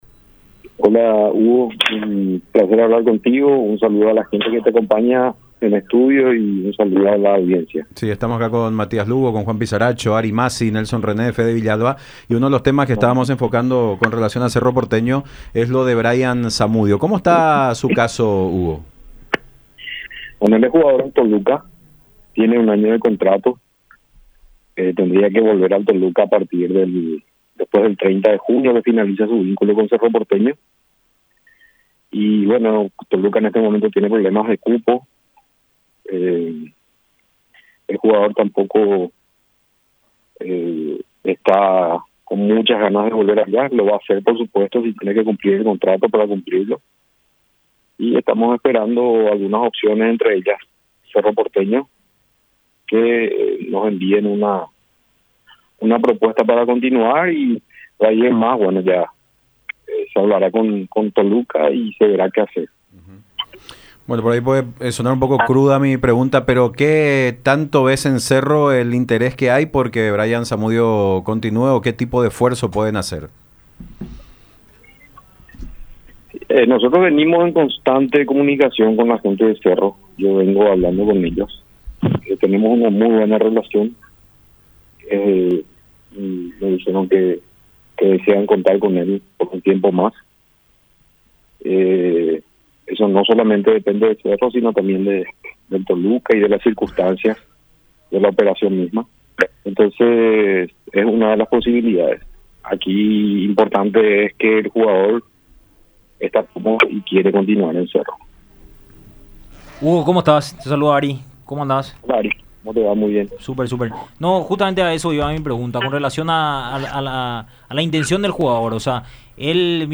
por radio la Unión y Unión TV.